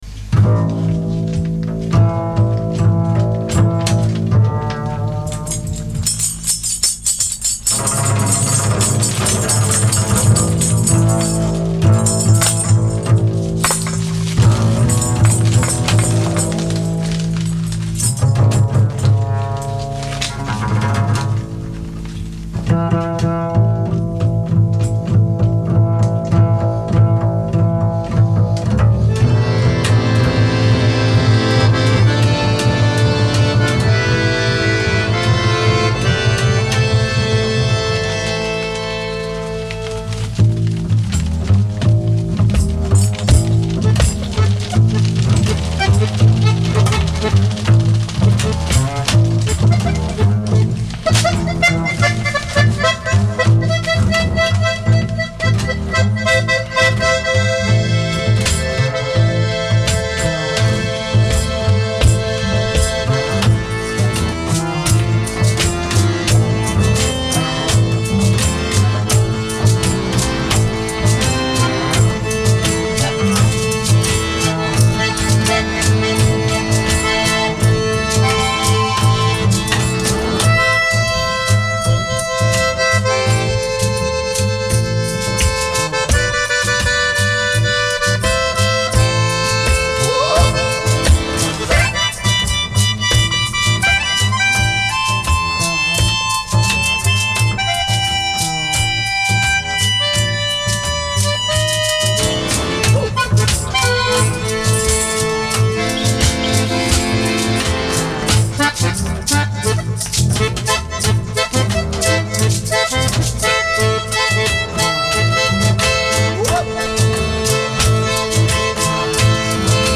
Joué et enregistré en direct